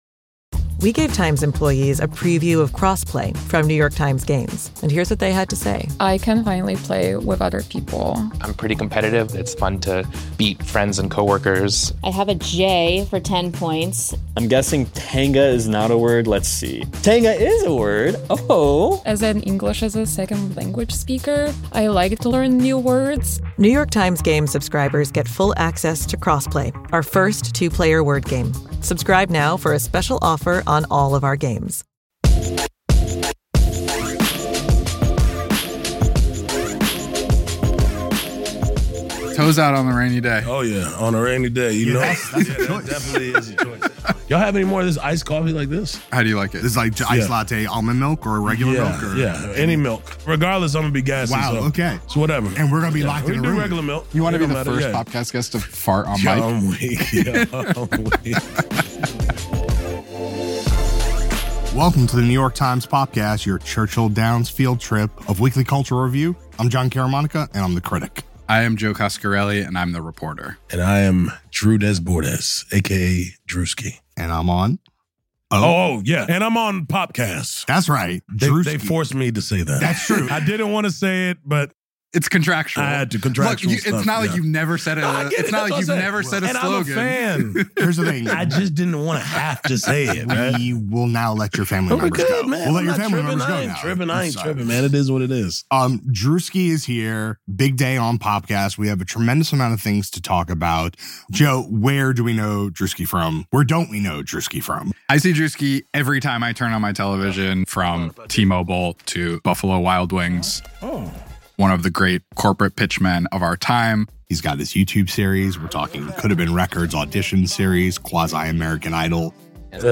Is This Druski’s Most Serious Interview Yet?